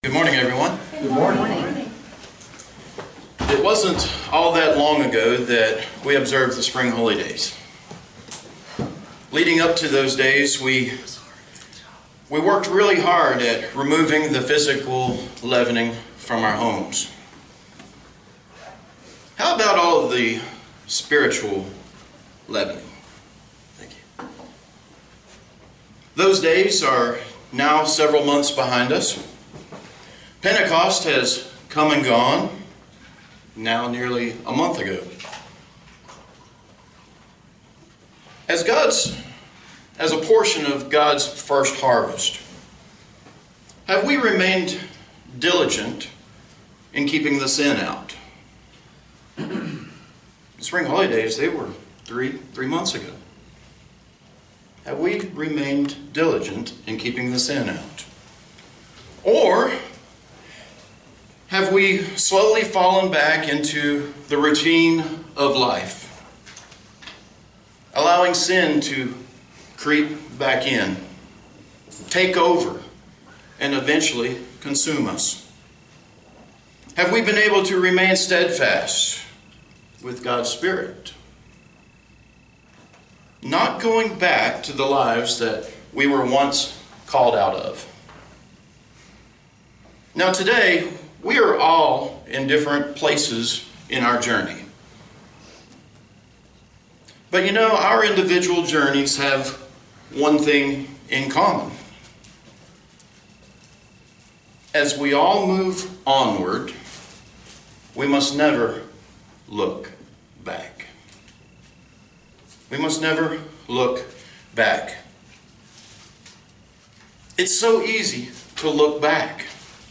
Sermons
Given in Roanoke, VA